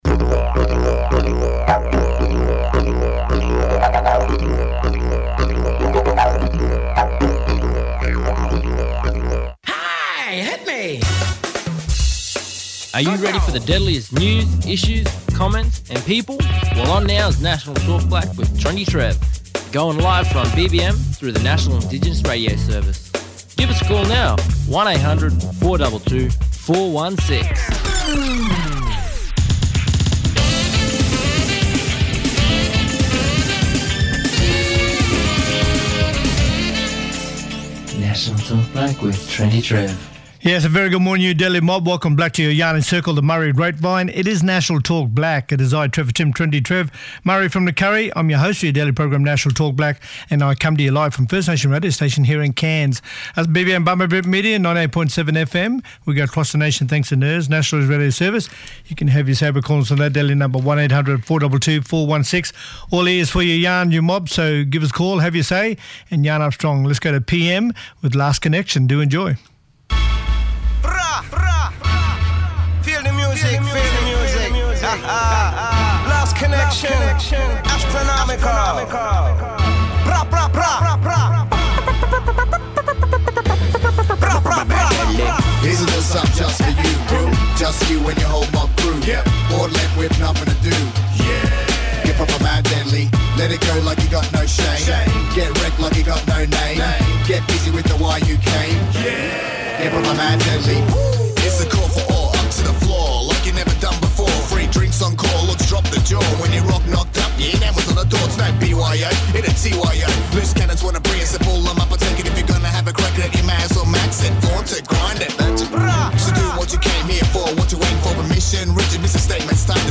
Many callers from across the nation.